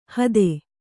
♪ hade